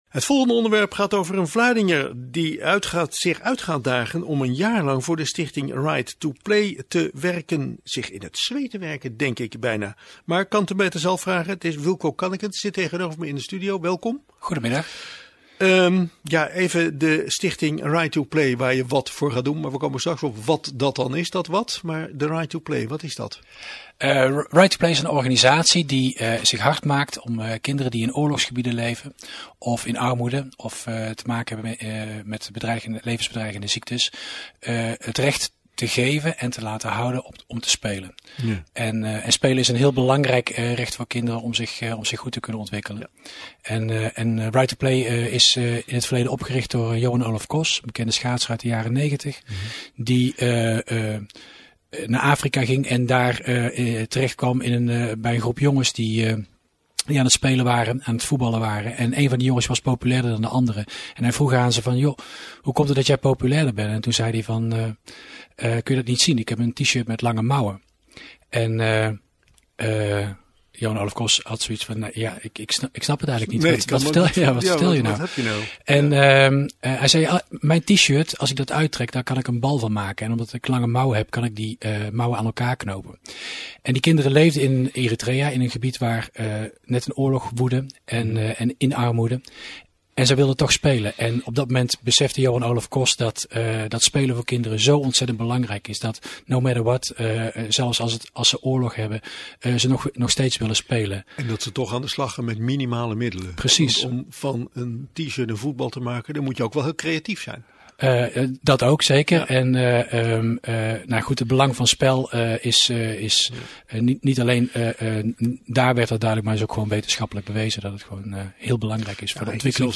Vanaf woensdag is er een aantal dagen een radio interview te horen op Omroep Vlaardingen.
Ik mag tot het einde van mijn actie elke maand terugkomen in de studio, om over de vorderingen van mijn actie te vertellen. Ik hoop met meer ervaring wat minder snel te praten en natuurlijk … minder “Uhh” te zeggen.